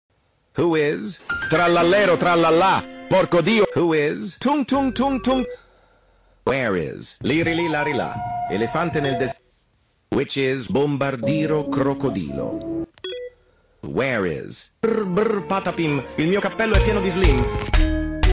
Tik Tok funny sound effects download mp3 Download Sound Effect Home